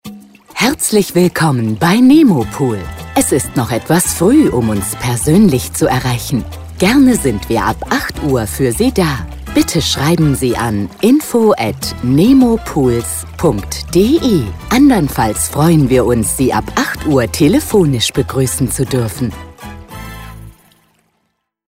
Ansage vor Öffnung